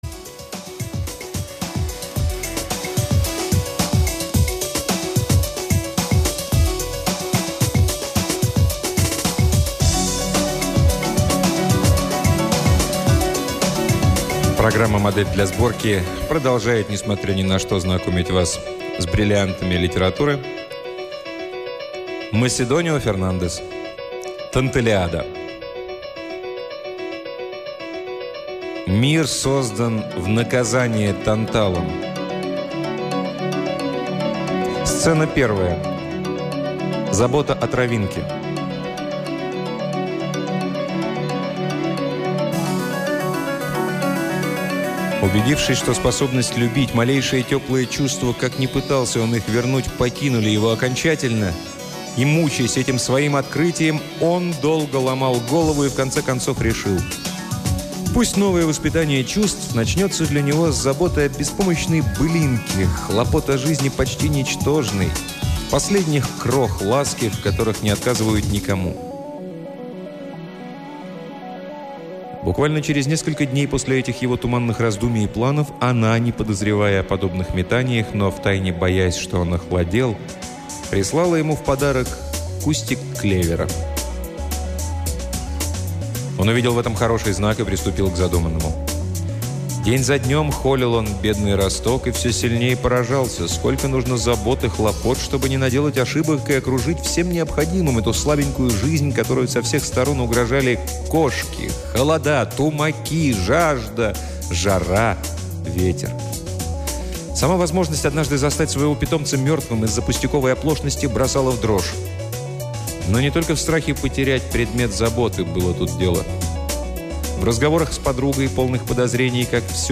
Аудиокнига Маседонио Фернандес — Танталиада